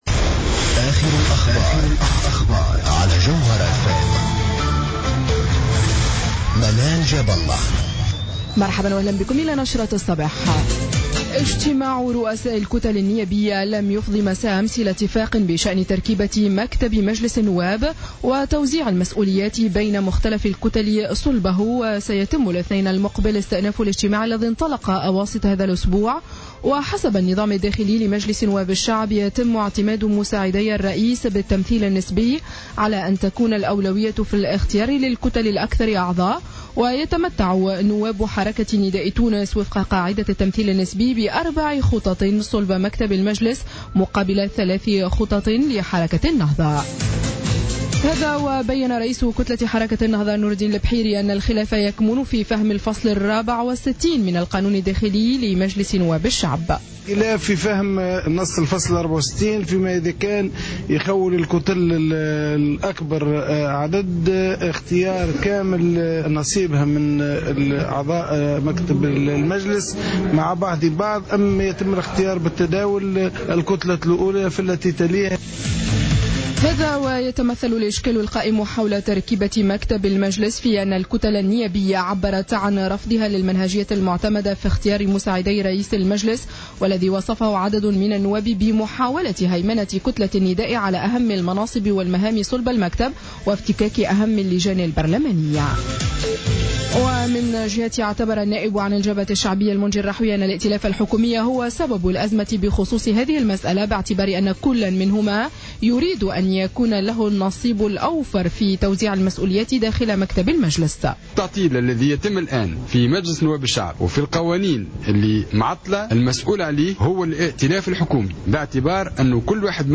نشرة أخبار السابعة صباحا ليوم السبت 14 فيفري 2015